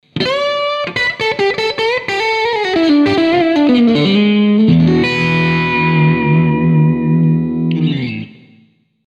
Ah, tutta questione di regolazioni, allora... ho chiuso io parecchio la gamma acuta spostando i microfoni sui coni perchè mi pareva debordante... può essere che l'abbia chiusa troppo!
Fammi capire meglio... (tutto come prima, solo microfoni spostati e qualche ritocco al parametrico...)
Crunch_LV3.mp3